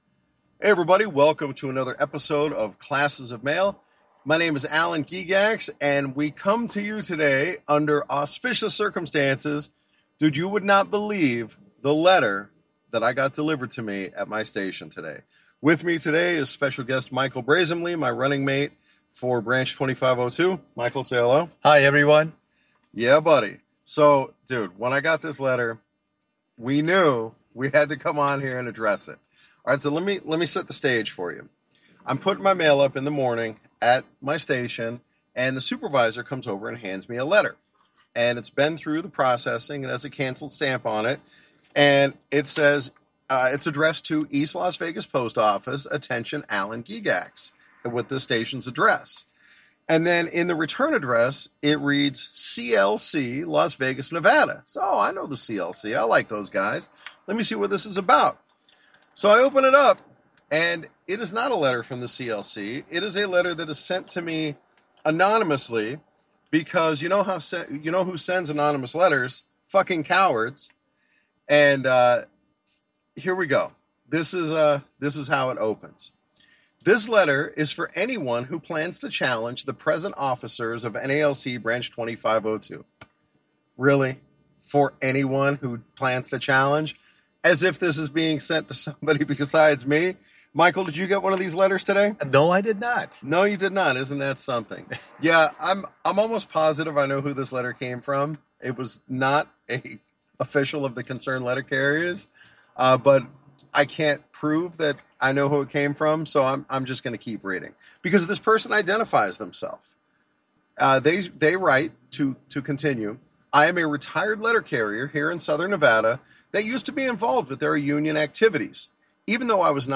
Sorry about the sound quality. It was recorded in the cab of a pickup truck!